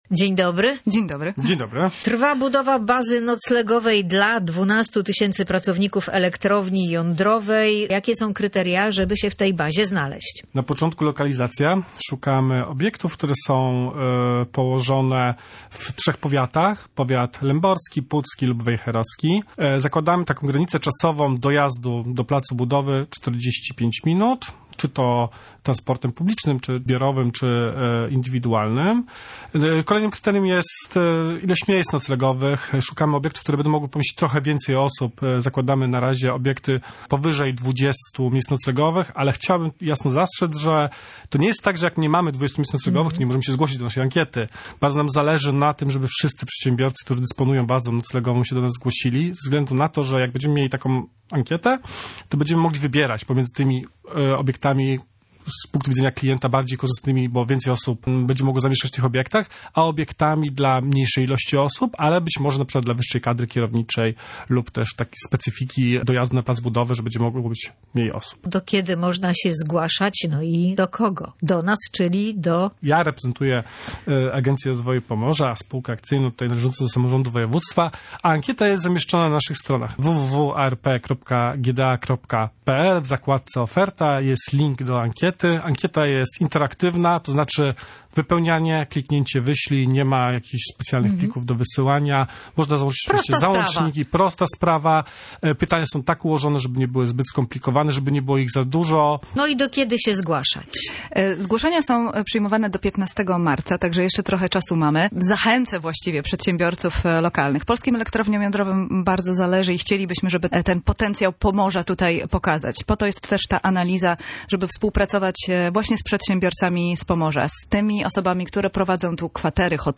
Wywiad_gospodarczy_elektrownia_jadrowa.mp3